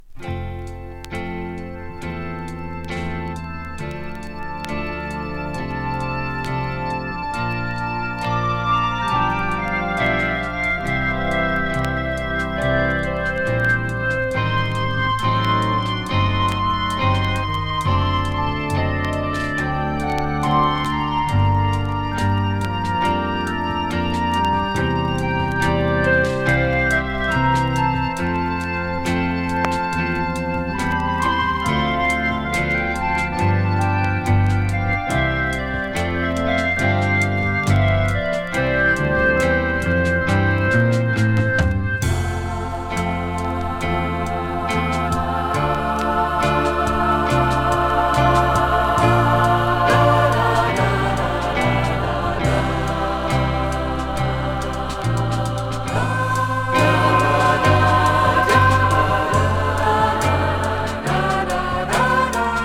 ドイツの電子音楽家らによるイージーリスニングアルバム。